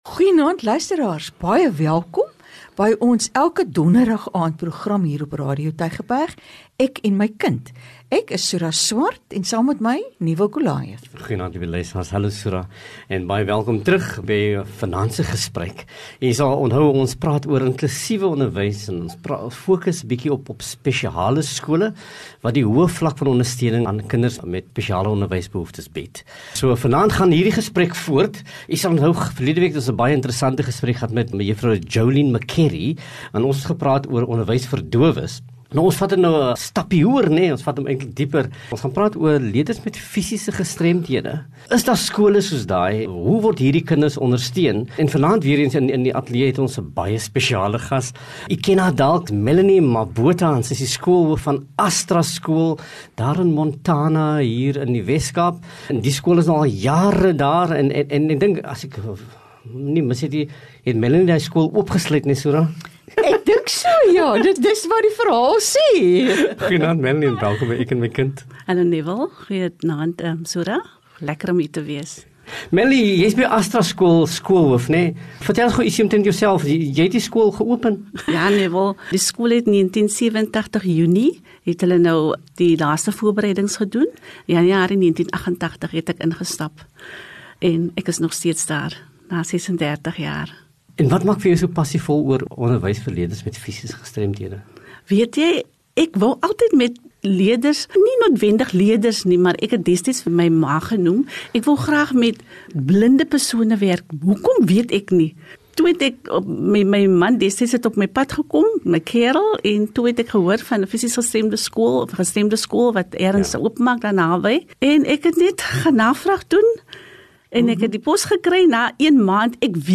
“Ek en my kind” is gespreksprogram wat vanuit ‘n onderwysperspektief ouerleiding bied ten opsigte van kinderontwikkeling en kindersorg.